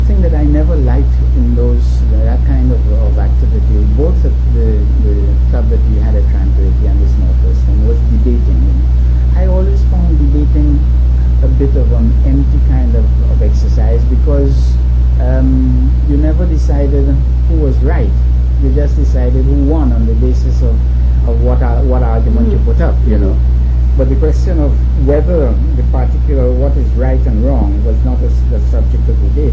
5 audio cassettes